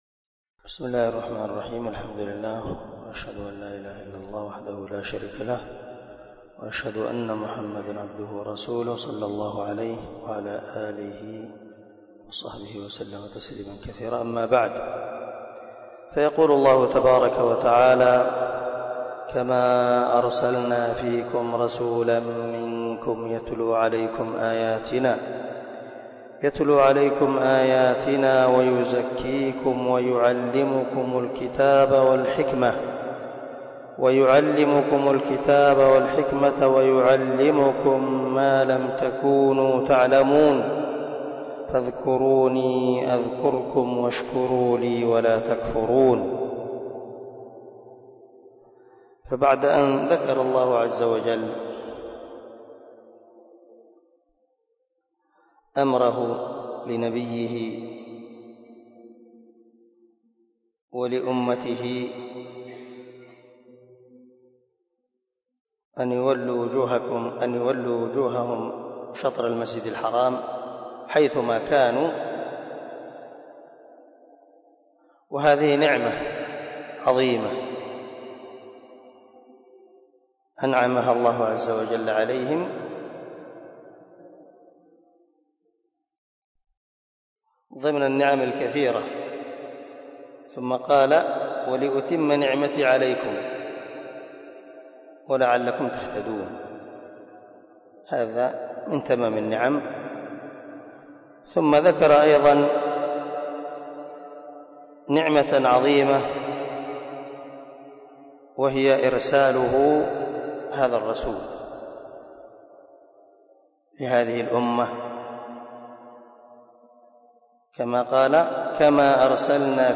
064الدرس 54 تفسير آية ( 151 – 152 ) من سورة البقرة من تفسير القران الكريم مع قراءة لتفسير السعدي